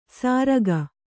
♪ sāraga